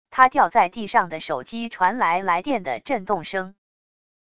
他掉在地上的手机传来来电的震动声wav音频